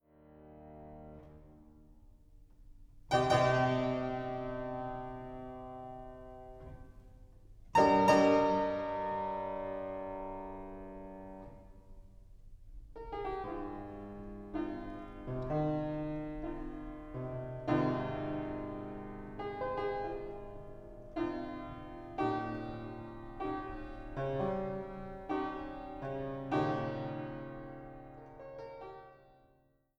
Klassische Posaune
Hammerklavier